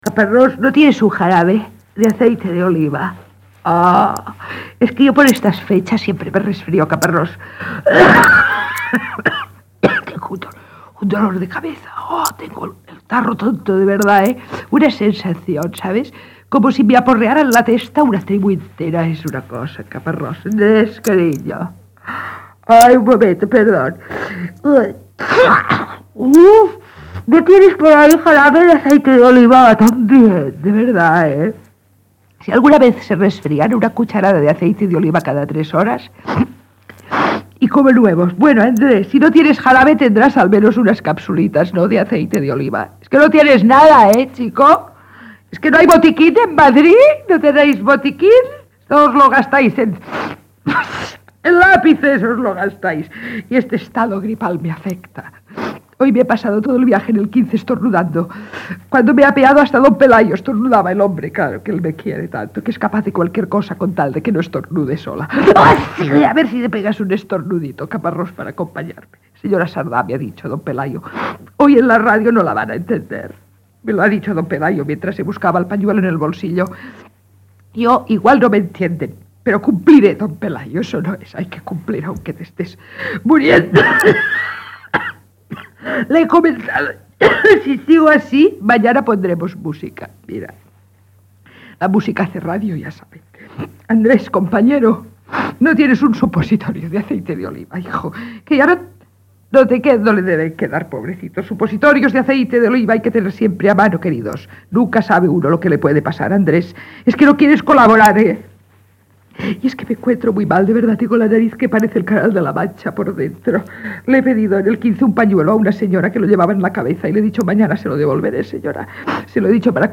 Monòleg humorístic publicitari per promocionar l'oli d'oliva, patrocinat per Patrimonio Comunal Olivarero.